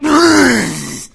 zo_pain2.wav